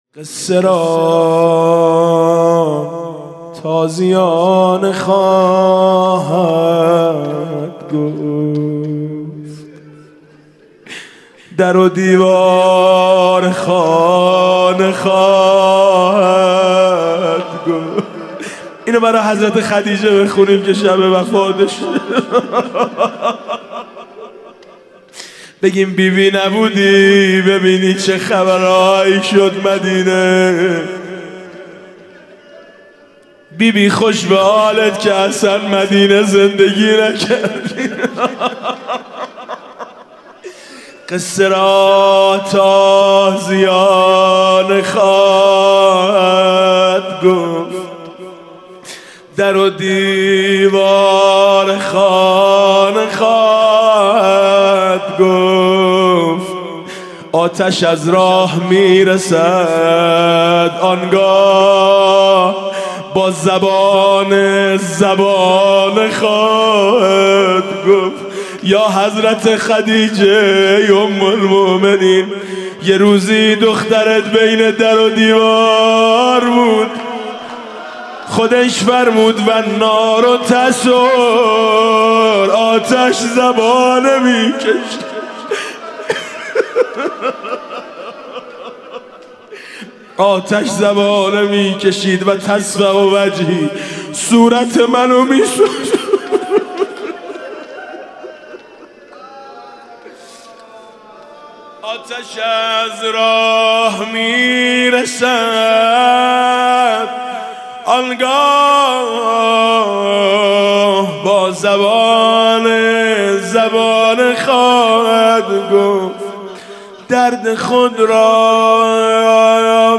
حاج میثم مطیعی/مراسم مناجات ماه مبارک رمضان